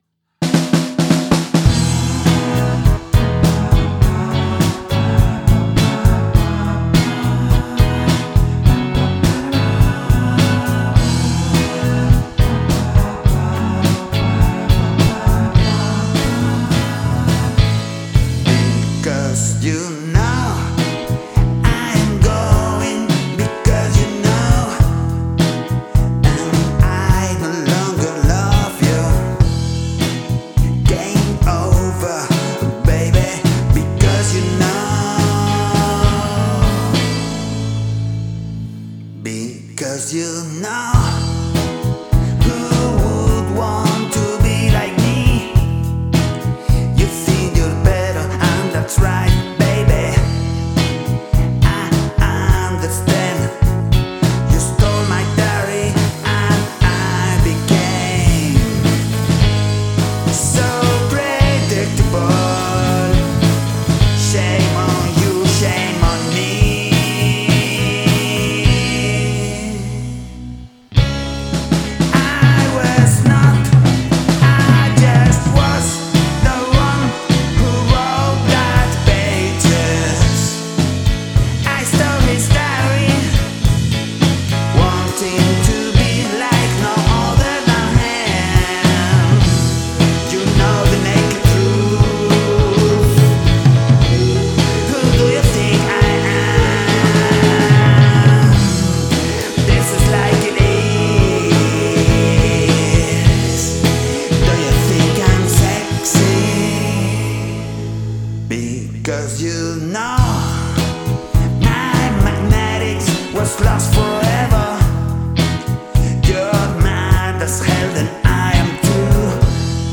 Vocal Harmony